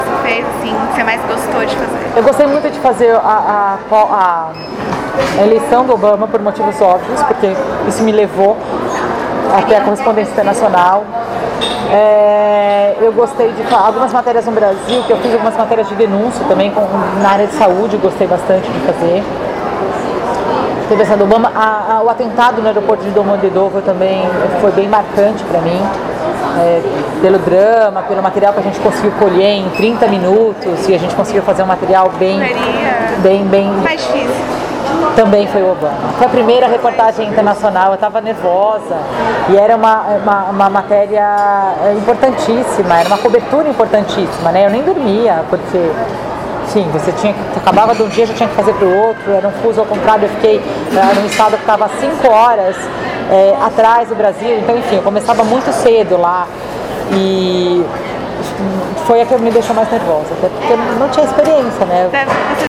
Marcamos a entrevista em um shopping de São Paulo e, munidas de câmeras e gravadores, iniciamos a entrevista.